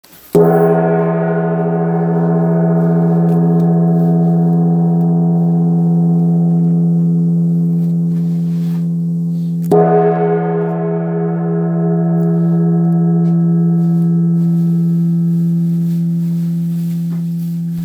Gong, Tibetan Handmade, Wind Gong, Flat Gong
Material Bronze
Unlike traditional gongs with upturned rims, the wind gong lacks a pronounced cylindrical shape, resulting in unobstructed vibrations that create a deep and immersive sound with a wide range of tonal qualities.